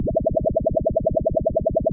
Low Warbling.wav